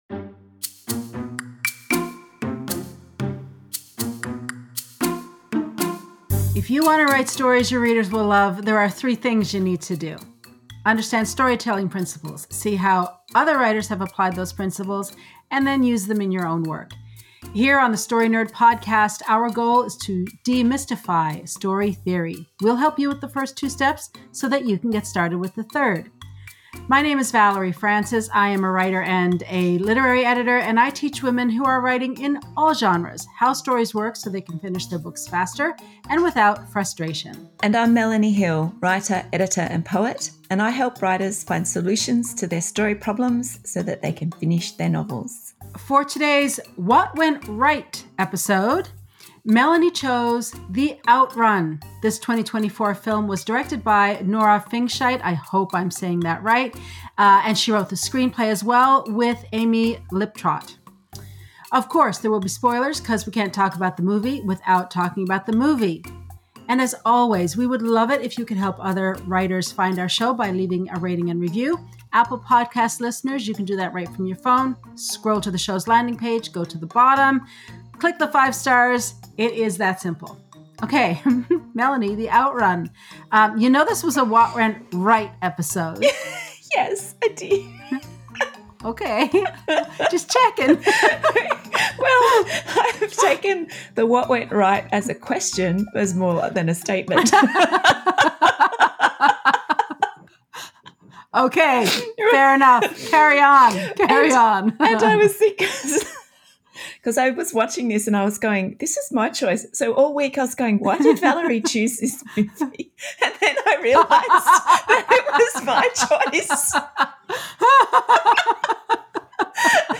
Watch this episode as a full video interview on our YouTube channel.